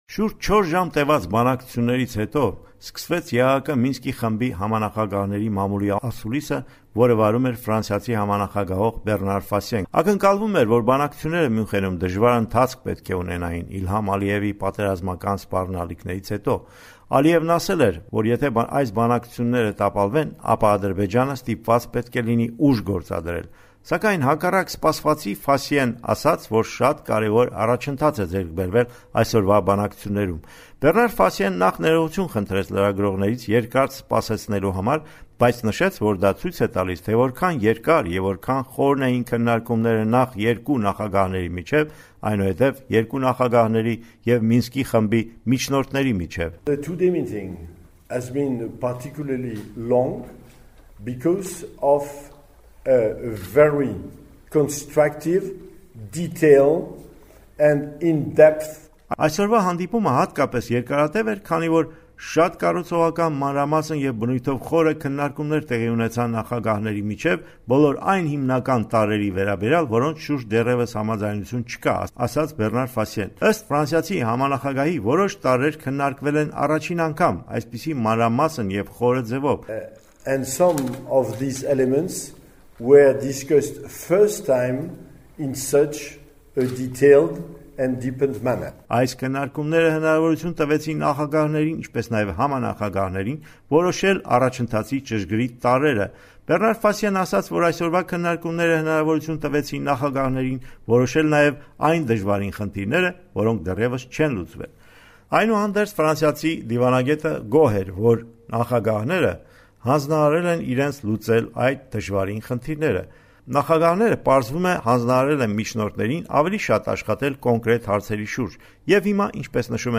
Միջնորդների ասուլիսը Մյունխենում